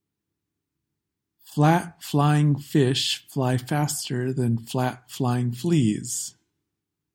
Tongue twister